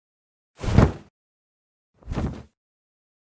Скатерть кладут на стол